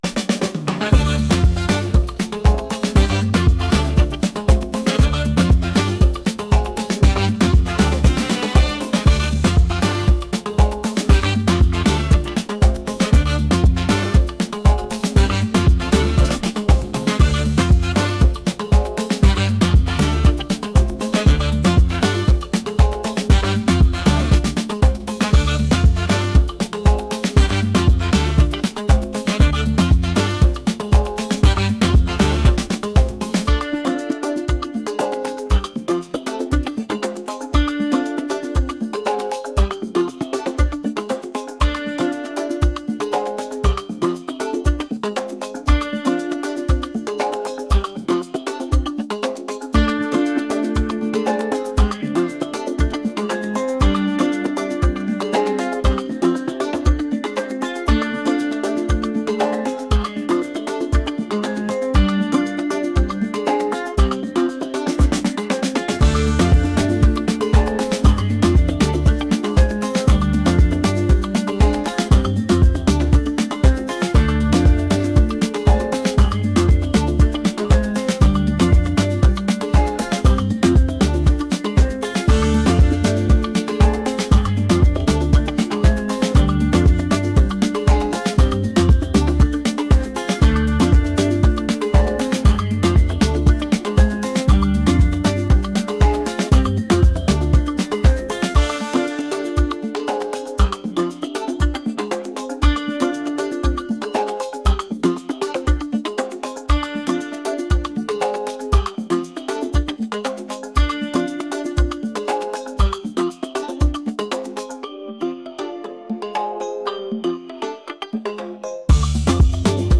energetic | rhythmic